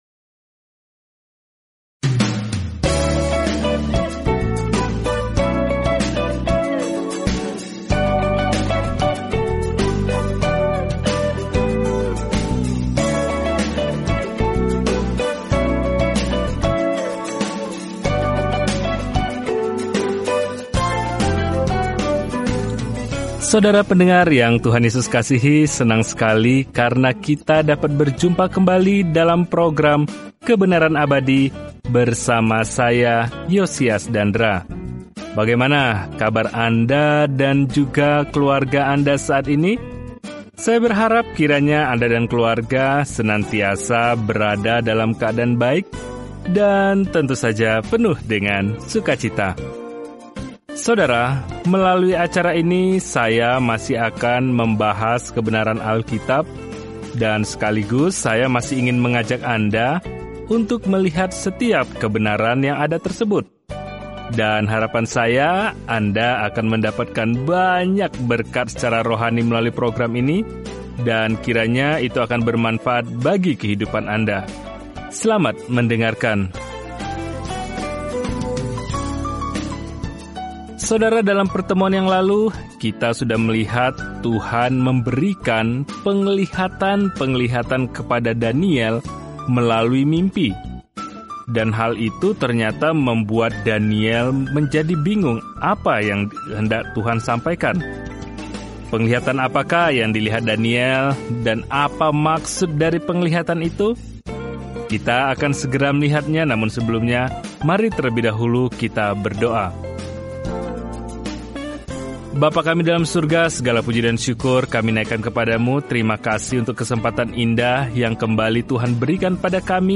Firman Tuhan, Alkitab Daniel 7:5-6 Hari 16 Mulai Rencana ini Hari 18 Tentang Rencana ini Kitab Daniel merupakan biografi seorang pria yang percaya kepada Tuhan dan visi kenabian tentang siapa yang pada akhirnya akan memerintah dunia. Telusuri Daniel setiap hari sambil mendengarkan studi audio dan membaca ayat-ayat tertentu dari firman Tuhan.